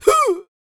D-YELL 1901.wav